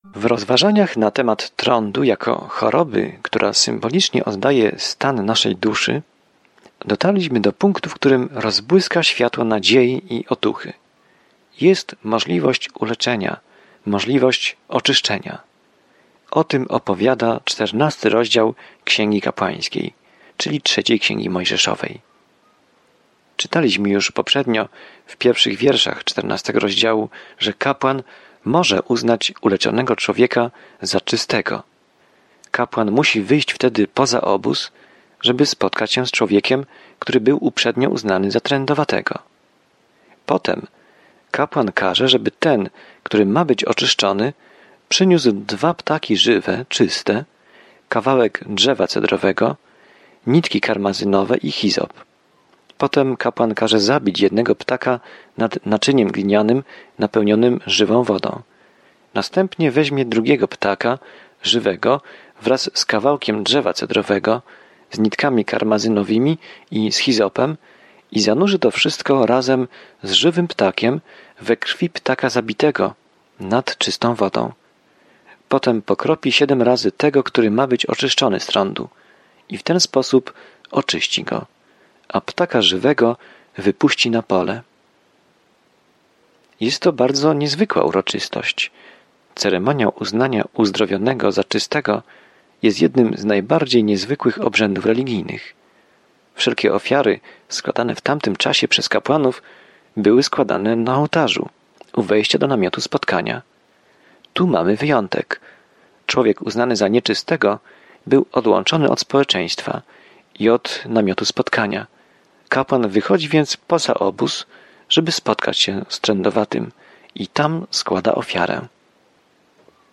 W oddawaniu czci, ofierze i czci Księga Kapłańska odpowiada na to pytanie starożytnego Izraela. Codziennie podróżuj przez Księgę Kapłańską, słuchając studium audio i czytając wybrane wersety słowa Bożego.